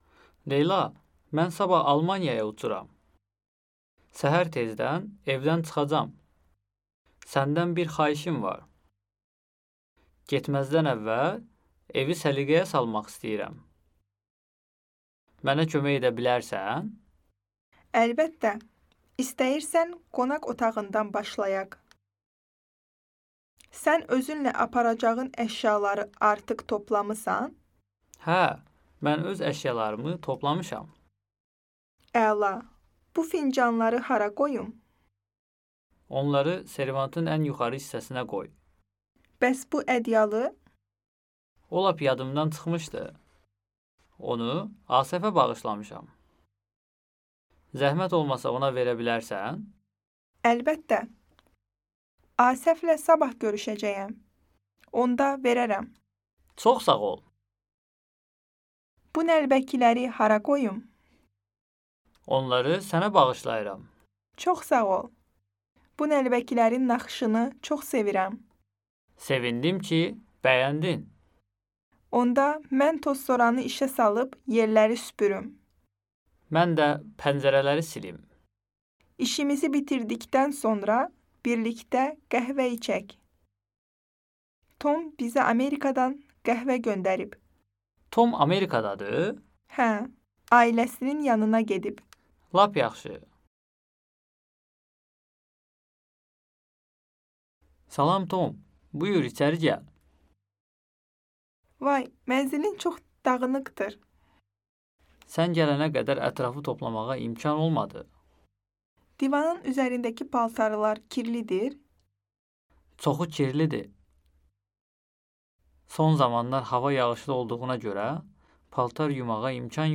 家の整理や道案内のときの会話を学びます。